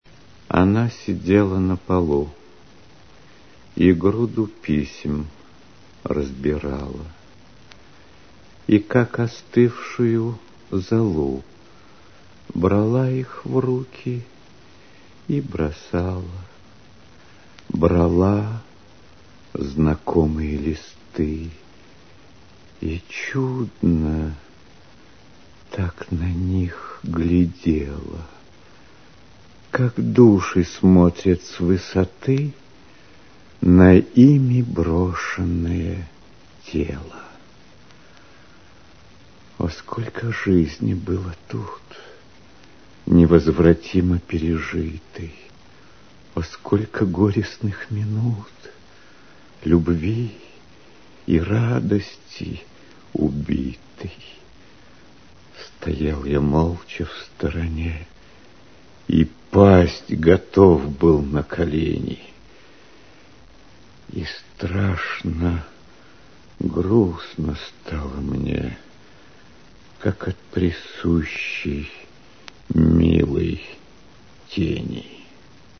Обожаю стихи в исполнении Смоктуновского.